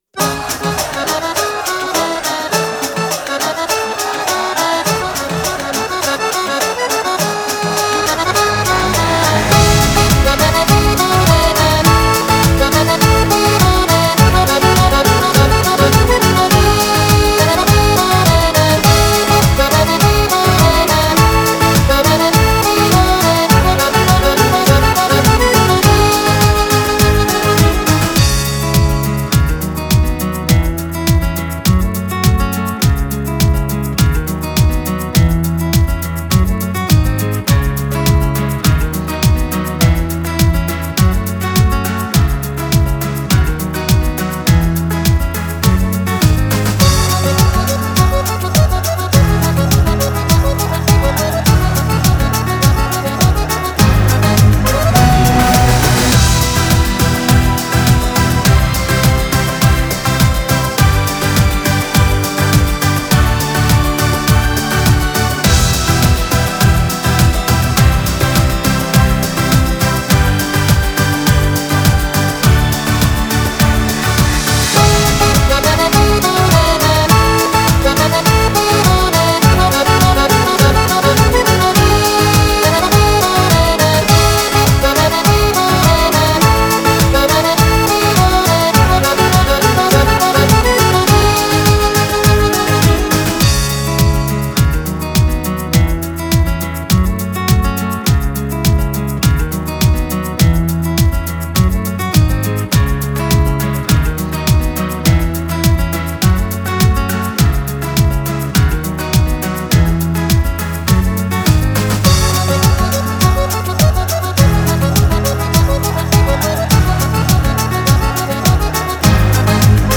con 15 canzoni tutte ballabili